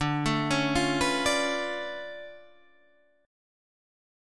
DmM13 Chord
Listen to DmM13 strummed